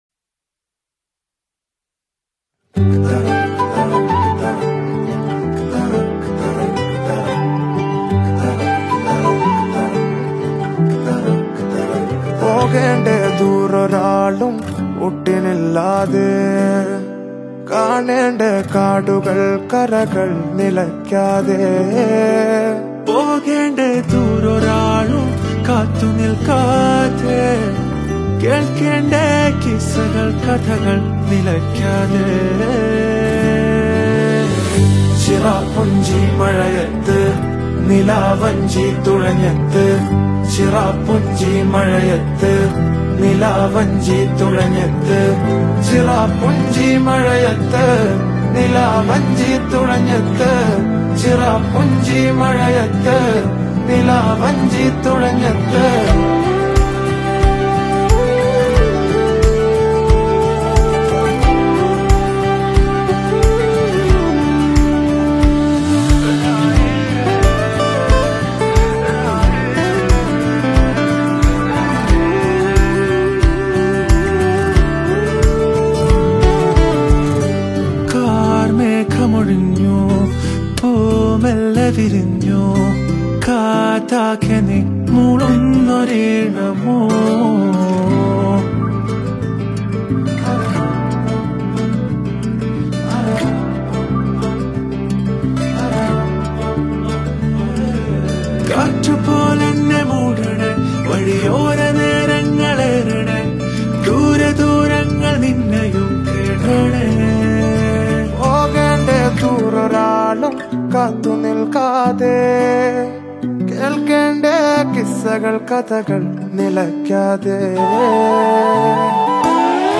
a poetic, emotional indie song
Malayalam Songs